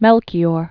(mĕlkē-ôr)